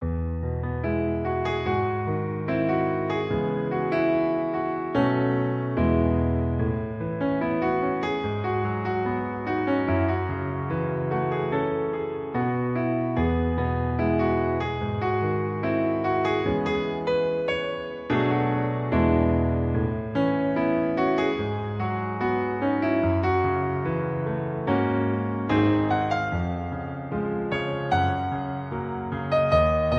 • Key: E Major
• Instruments: Piano solo
• Genre: Pop, Film/TV